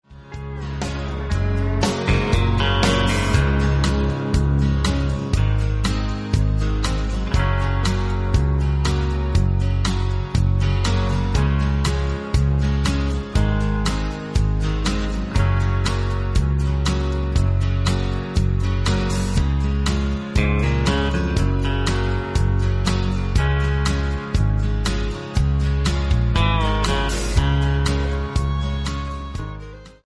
MP3 – Original Key – Backing Vocals Like Original